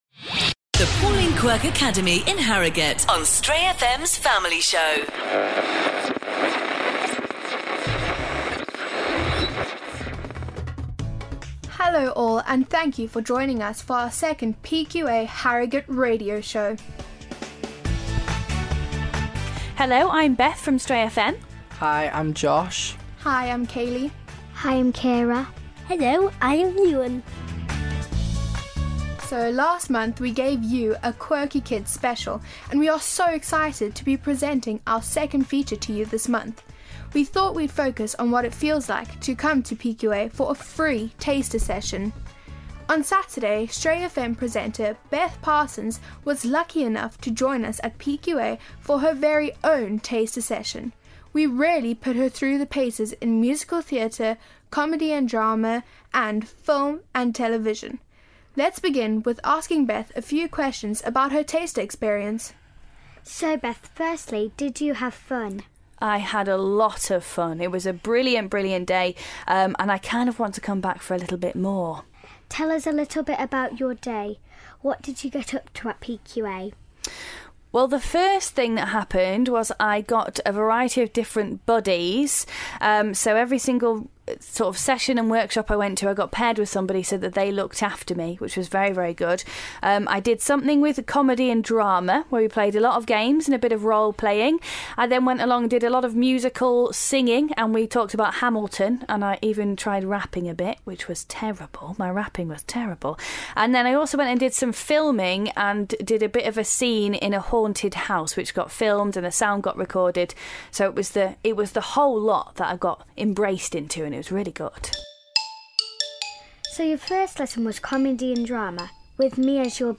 She danced, acted, sung and even rapped... and all significantly worse than the kids.